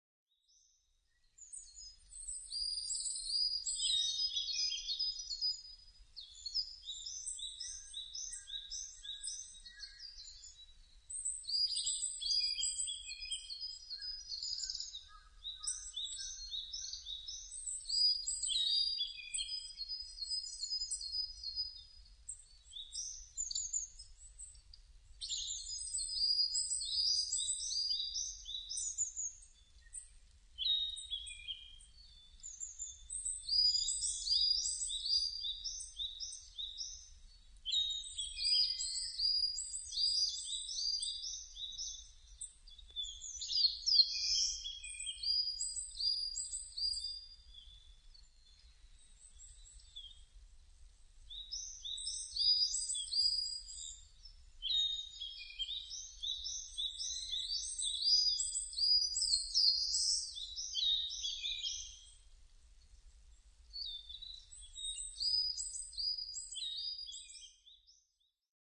囀り　Singing
日光市稲荷川中流　alt=730m  HiFi --------------
Mic.: Sound Professionals SP-TFB-2  Binaural Souce
コガラ・ヒガラ・キビタキ・コゲラ・シジュウカラ・センダイムシクイ・ウグイス・ハシブトガラス